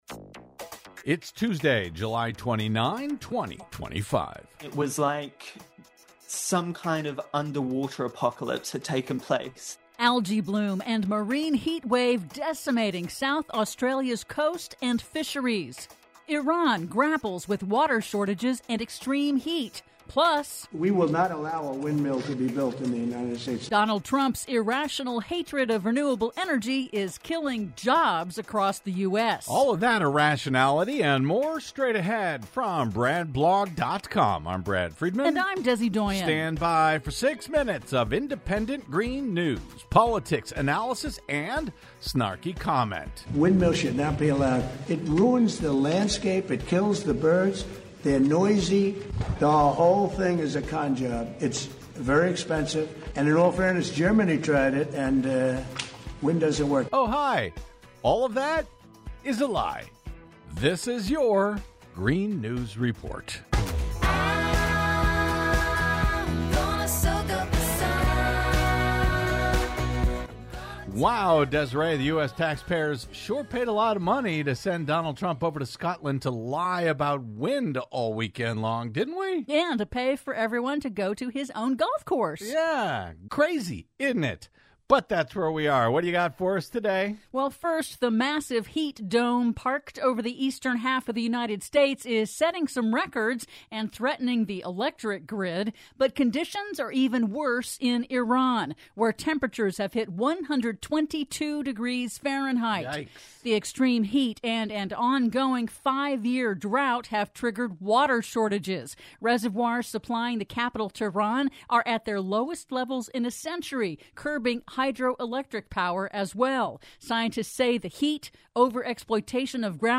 IN TODAY'S RADIO REPORT: Massive algae bloom and marine heat wave are decimating South Australia's coast and fisheries; Iran grapples with water crisis and extreme heat; Wind-driven wildfires force evacuations in Greece, Turkey; PLUS: Donald Trump's irrational hatred of wind energy is killing jobs across the U.S.... All that and more in today's Green News Report!